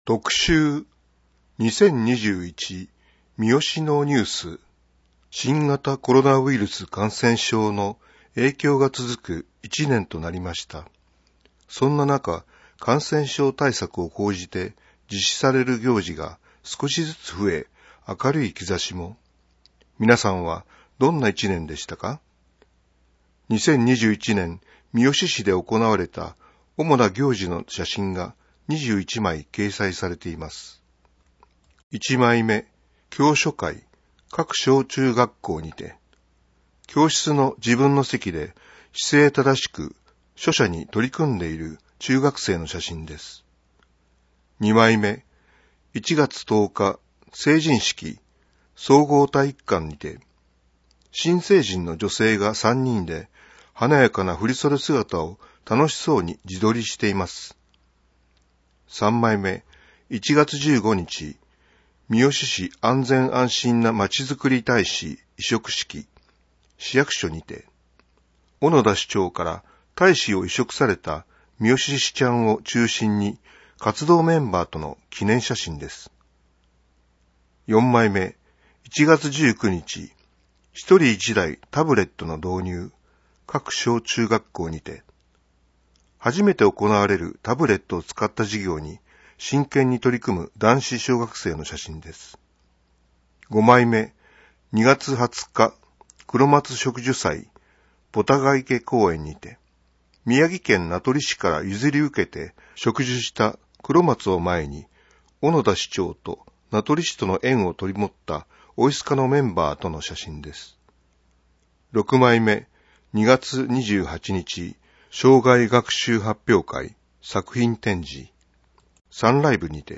声の広報
声の広報は、市内で活動しているボランティアグループ「やまびこの会」の皆さんにより、広報みよしの内容を音訳（録音）していただき、目の不自由な皆さんへCDでお届けしています。